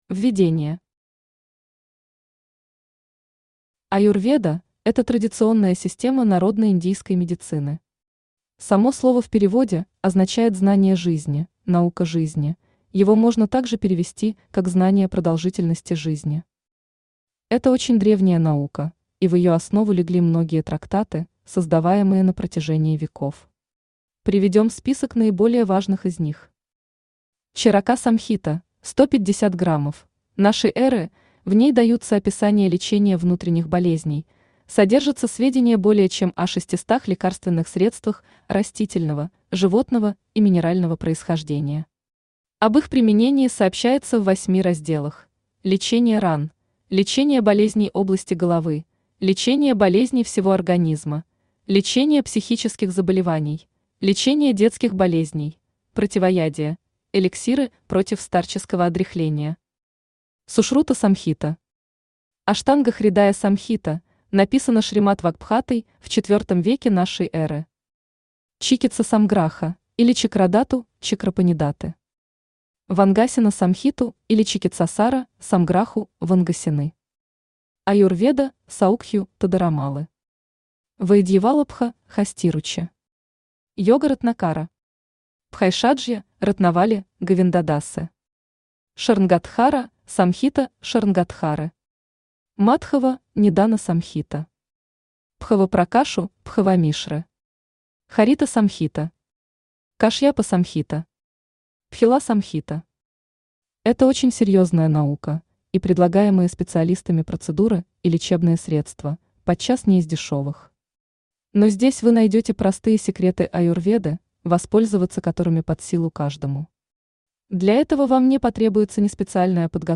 Аудиокнига Часы Аюрведы. Путь к гармонии | Библиотека аудиокниг
Путь к гармонии Автор Ашвани Вишвамитра Читает аудиокнигу Авточтец ЛитРес.